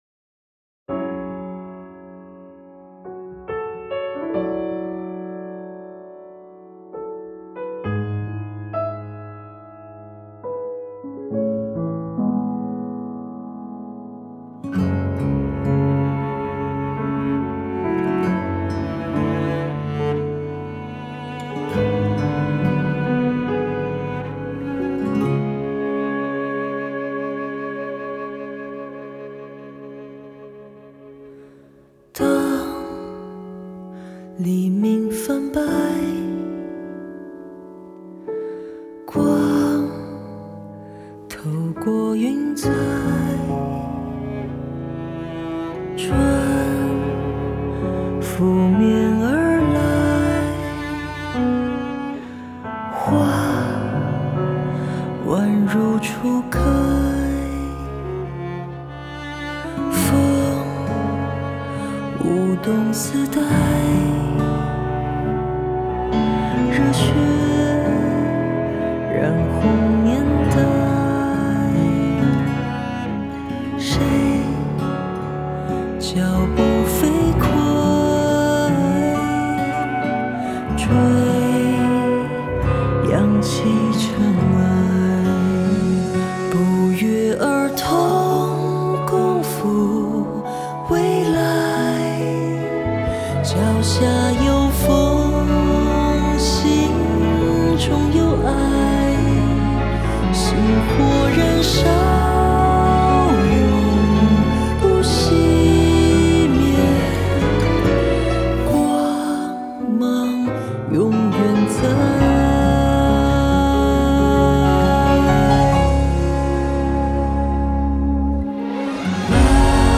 女生版
吉他
弦乐
大提琴
合唱